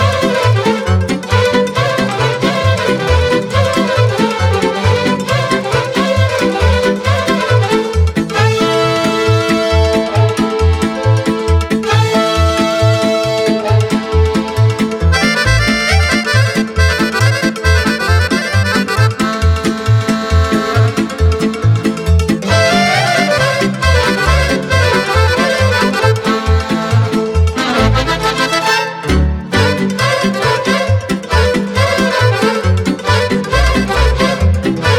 Traditional Folk
Жанр: Фолк / Инструмантальные